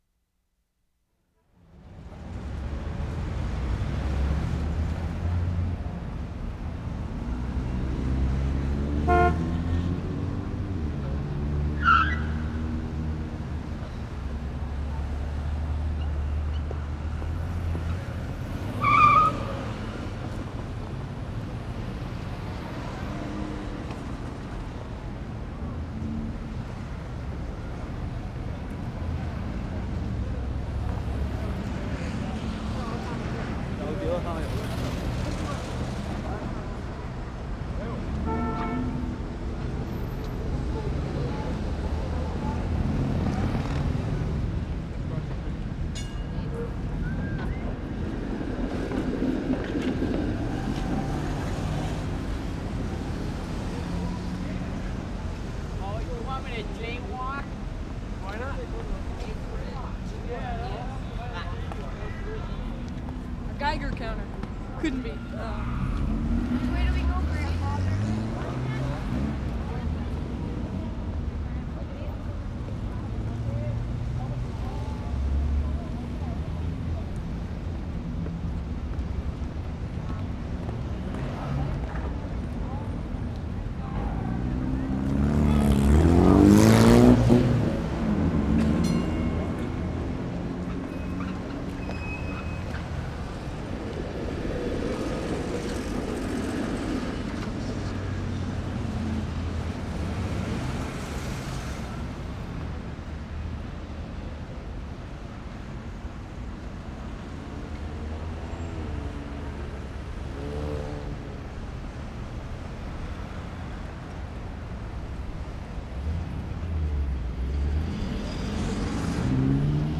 TORONTO, ONTARIO Nov. 3, 1973
TRAFFIC AND STREETCARS 2'05"
6. Same Saturday night ambience. Car horn (0'08"), brakes and tires (0'15"), car horn (0'40"), ding and swish of streetcar (0'45") , "do you want me to jaywalk?" (0'50") , "a geiger counter" (1'05" referring to recordists' machine), hot rodder 1'20"), ding and swoosh of another streetcar (1'25"). Very busy ambience.